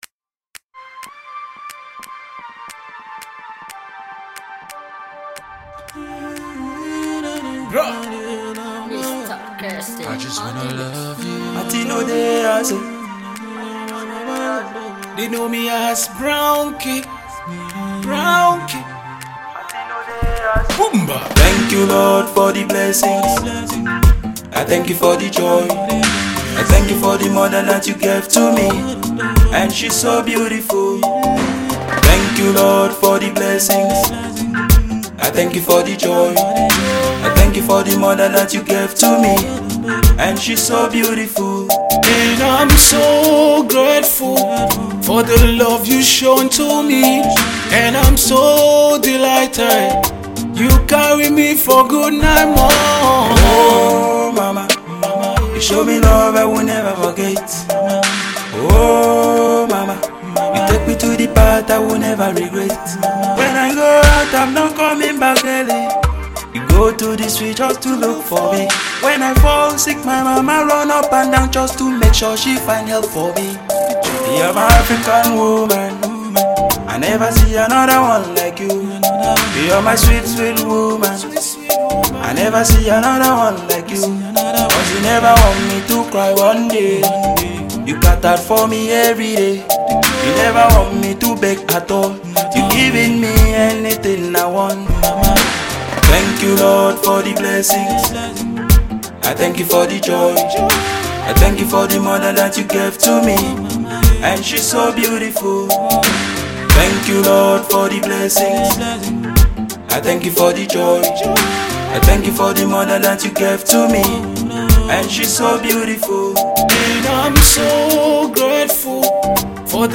The Nigeria fast street singer